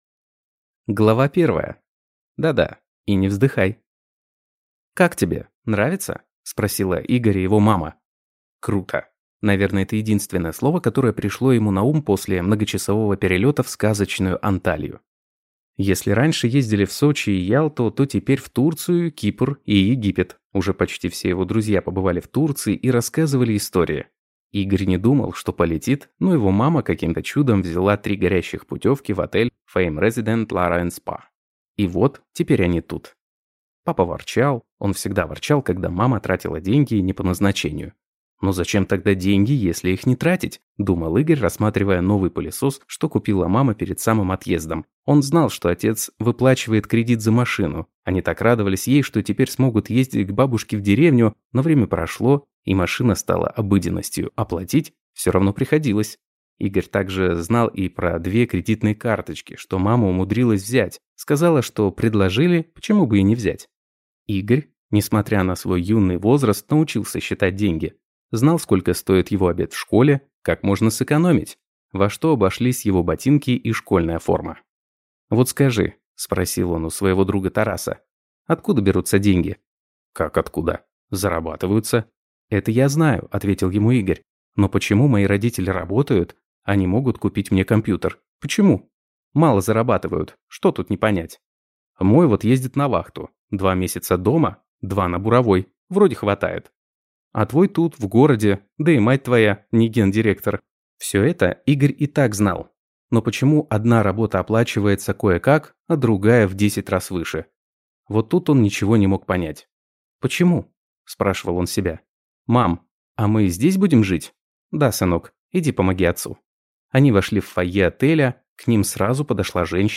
Aудиокнига Sex-машина